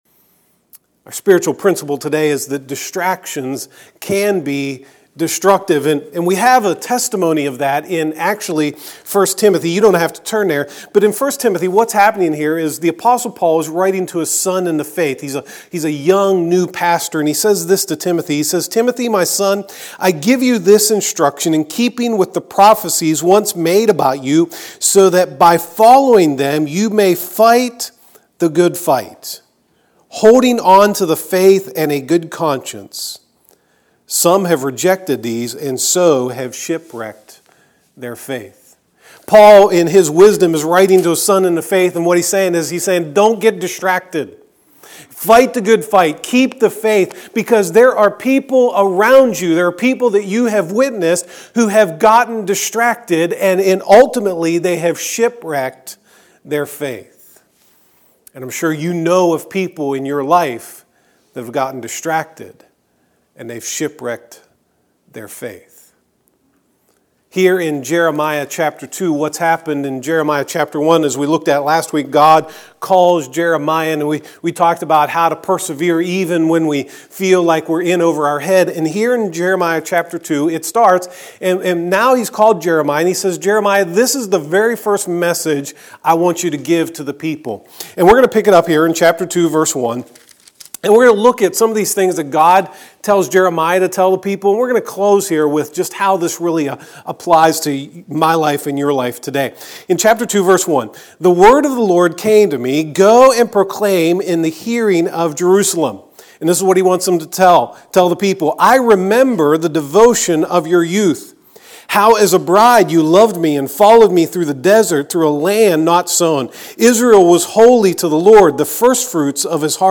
2018 Persevere When You Go Astray Preacher